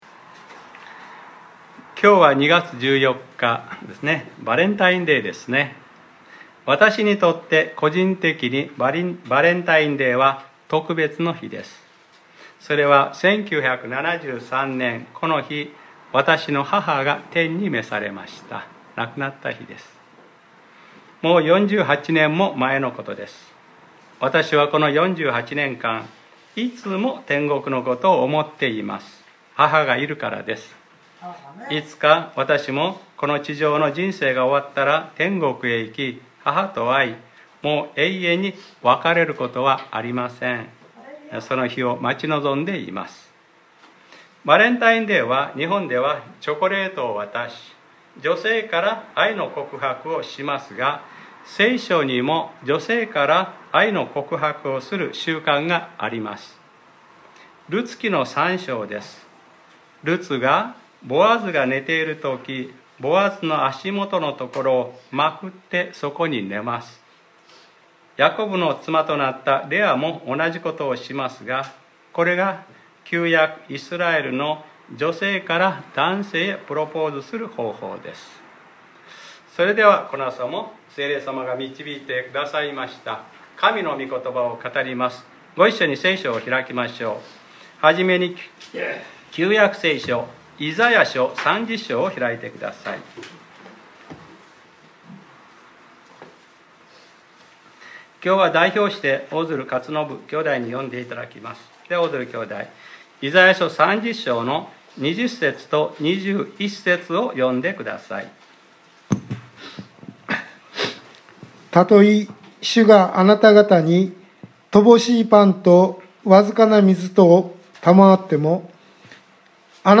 2021年02月14日（日）礼拝説教『 これが道だ これに歩め 』